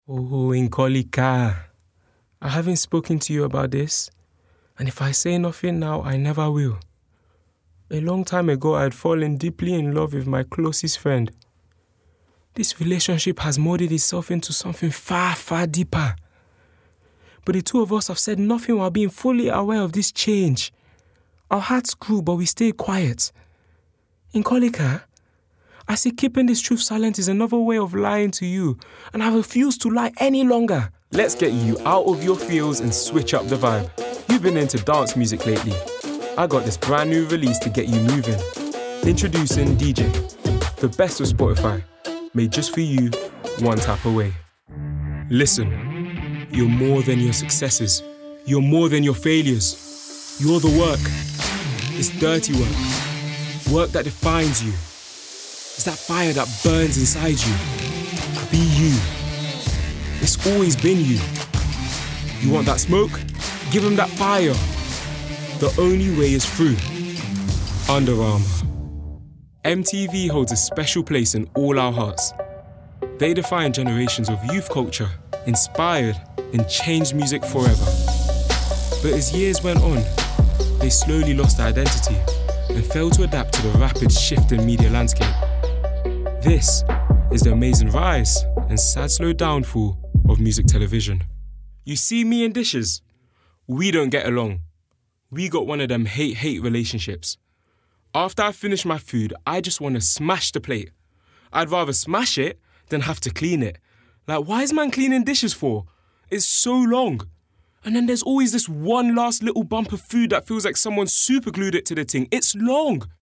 British
Native voice:
MLE
Voicereel: